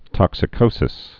(tŏksĭ-kōsĭs)